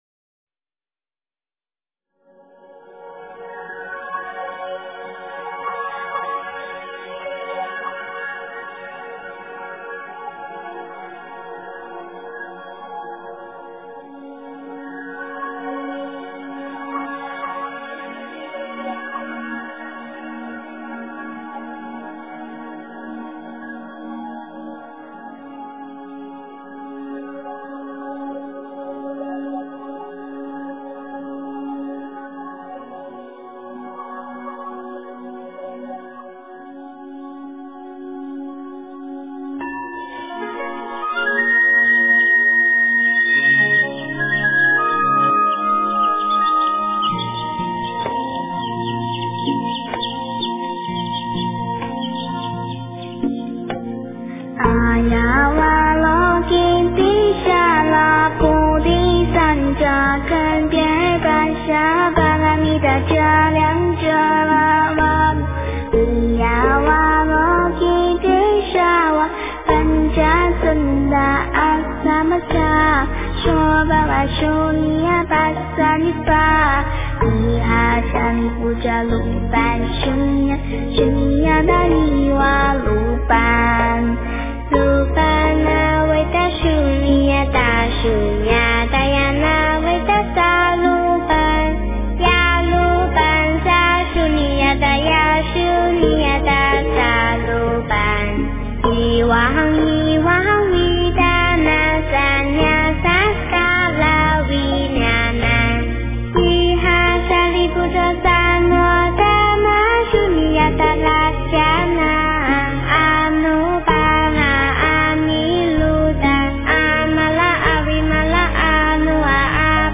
心经-梵语 诵经 心经-梵语--十八一心合唱团 点我： 标签: 佛音 诵经 佛教音乐 返回列表 上一篇： 心经 下一篇： 般若心经-圆融版 相关文章 心经 心经--唱经给你听...